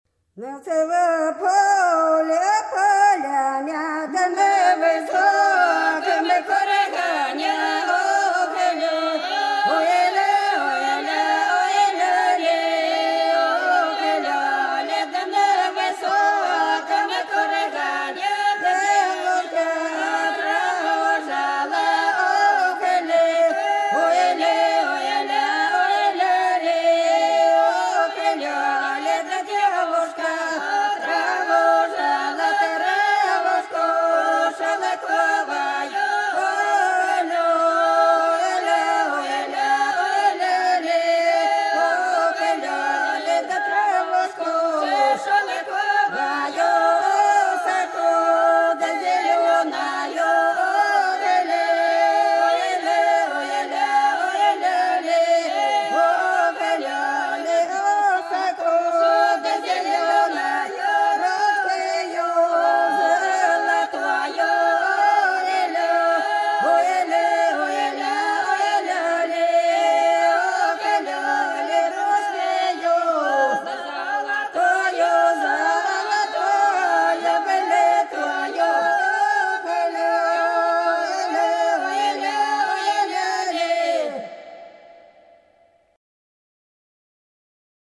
Долина была широкая (Поют народные исполнители села Нижняя Покровка Белгородской области) В нас на поле, на поляне - таночная